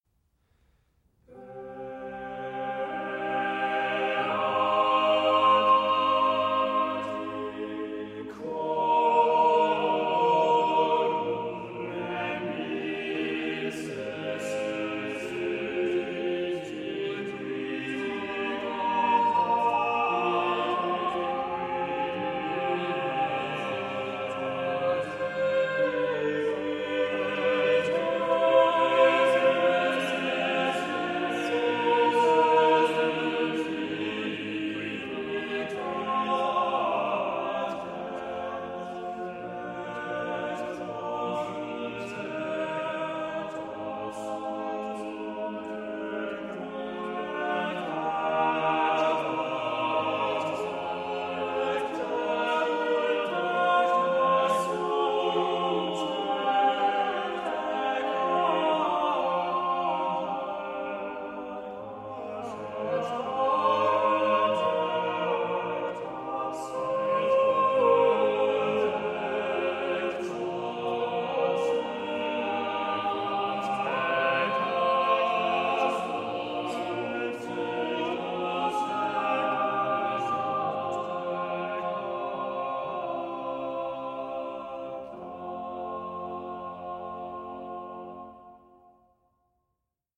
suddenly changes the pace and intensity, and texture
Now dost make in slow step the voices drag, hesitate
Now again hasting, speedy dost drive the rhythms on.
Now jubilant, now restrained, as the words require …